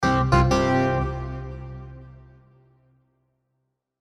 Victory SoundFX3.wav